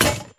breath.ogg